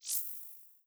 Modern UI SFX / SlidesAndTransitions
LittleSwoosh5.wav